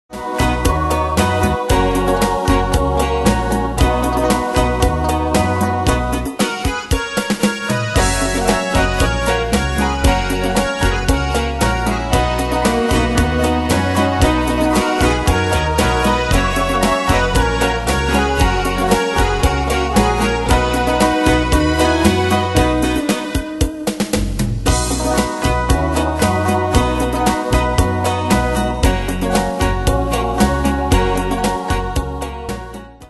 Style: Retro Année/Year: 1963 Tempo: 115 Durée/Time: 2.58
Danse/Dance: ChaCha Cat Id.
Pro Backing Tracks